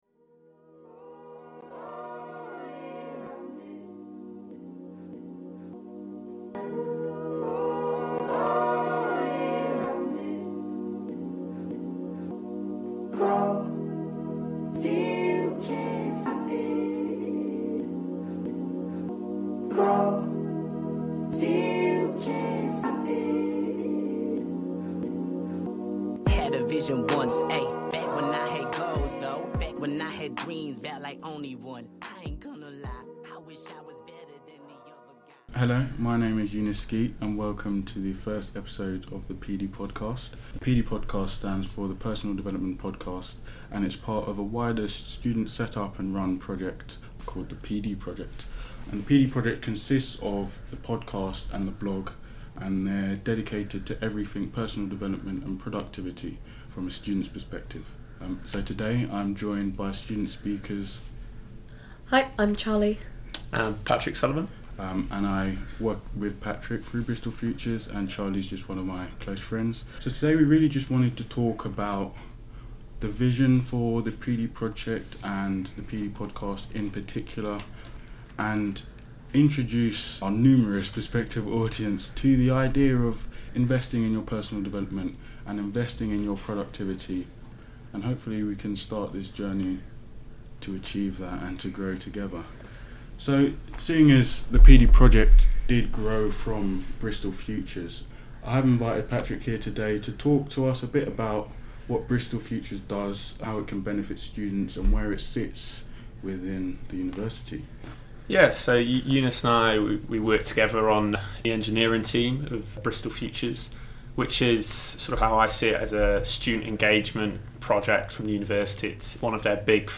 Guest speakers will discuss their journeys with Personal Development and Productivity and talk about tailoring your Personal Development to your needs.